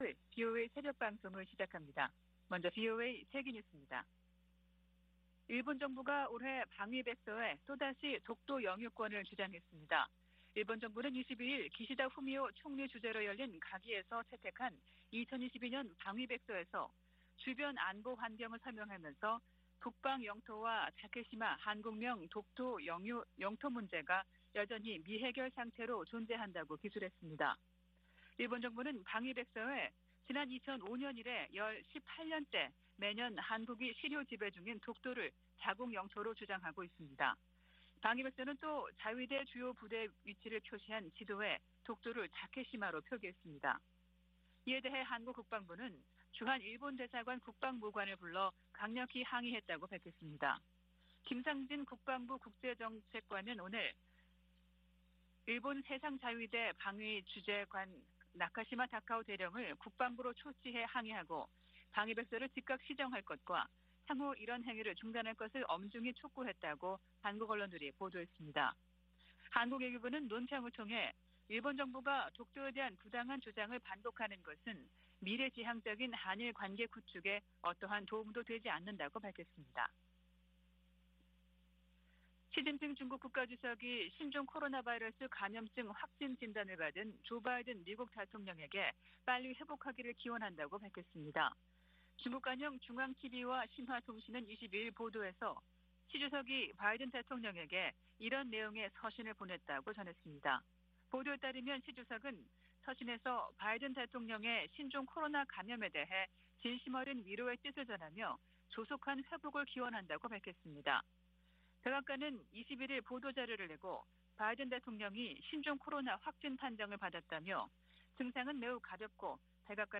VOA 한국어 '출발 뉴스 쇼', 2022년 7월 23일 방송입니다. 한국 국방부는 대규모 미-한 연합연습과 야외기동훈련을 올해부터 부활시킬 방침이라고 밝혔습니다. 미국은 한국과 일본의 핵무장을 절대 지지하지 않을 것이라고 고위 관리가 전망했습니다. 미 공화당 상원의원들이 로이드 오스틴 국방장관에게 인도태평양 지역 미사일 배치를 위해 한국 등 동맹국들과 협력하라고 촉구했습니다.